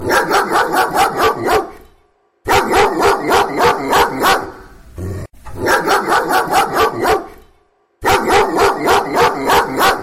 Hundegebell klingelton kostenlos
Kategorien: Tierstimmen